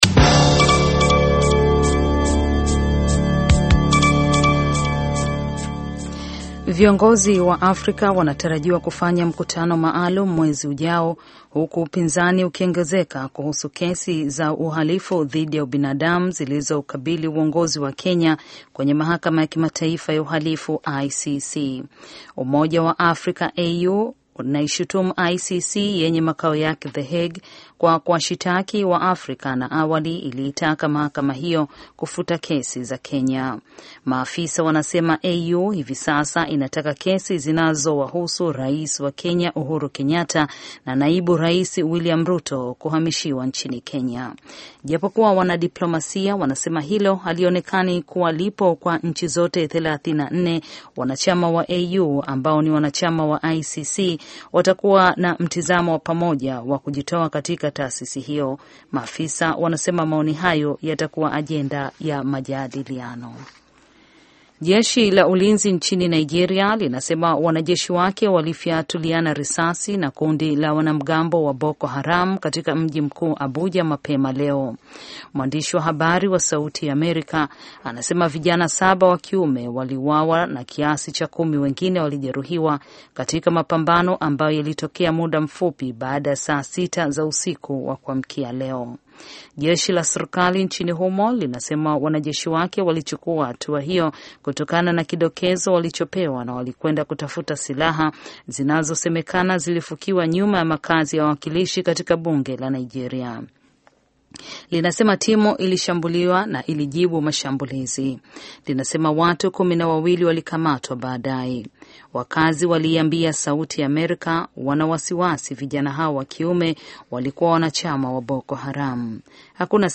Taarifa ya Habari VOA Swahili - 6:00